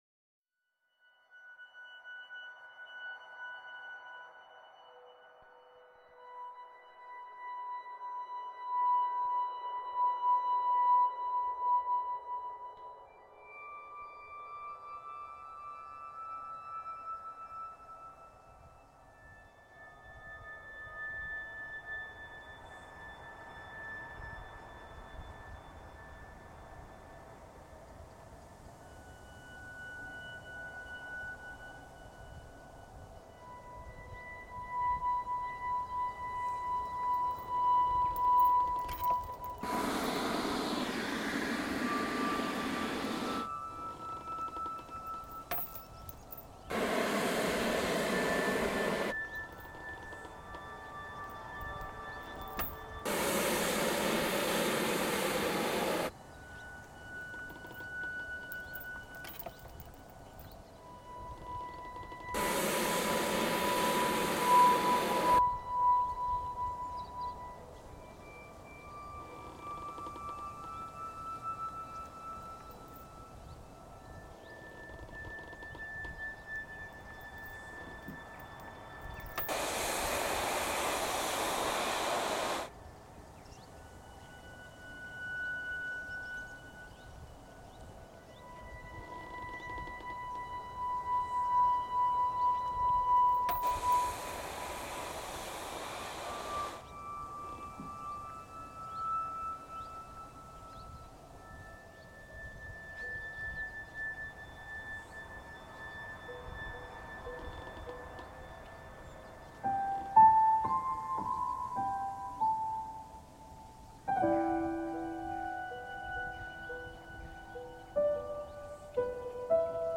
Electro-Acoustic